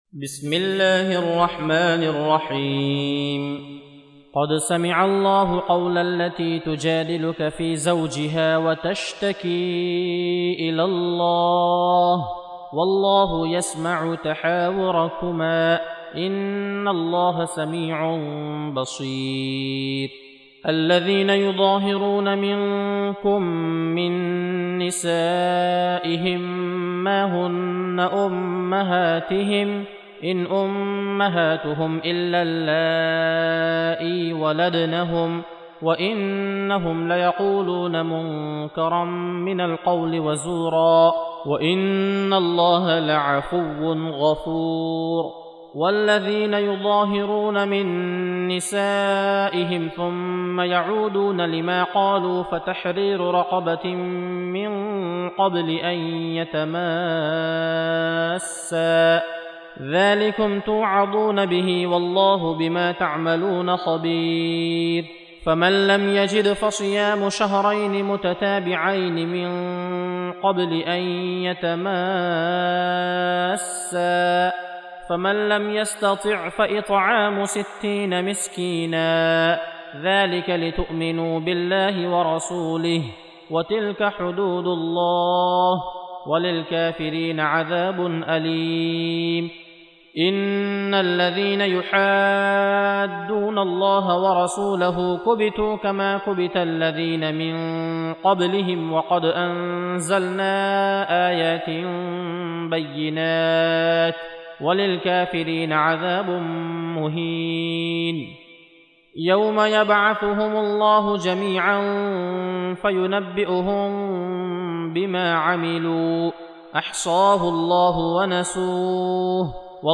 58. Surah Al-Muj�dilah سورة المجادلة Audio Quran Tarteel Recitation
حفص عن عاصم Hafs for Assem